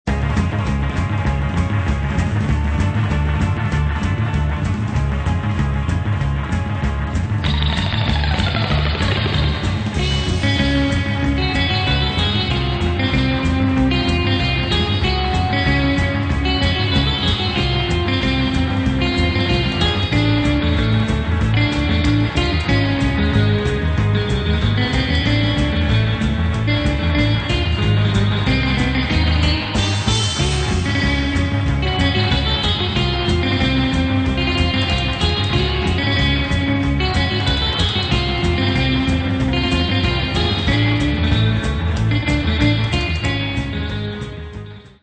exciting fast instr.